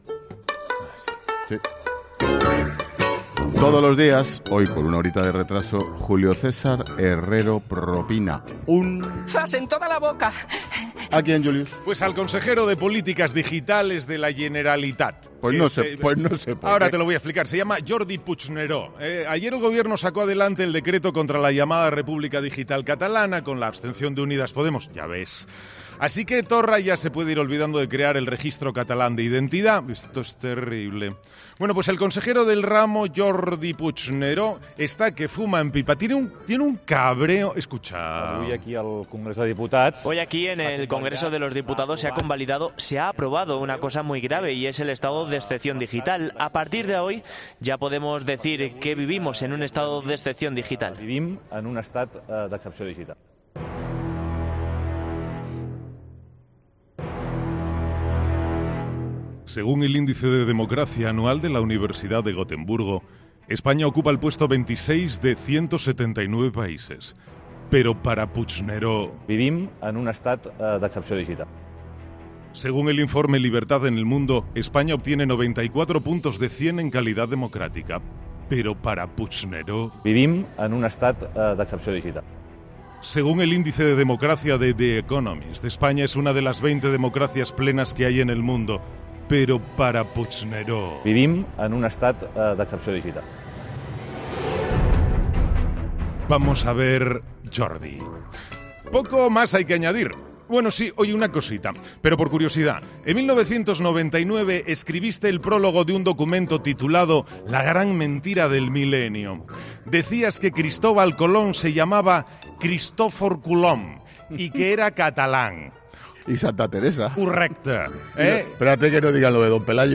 Declaraciones de Jordi Puigneró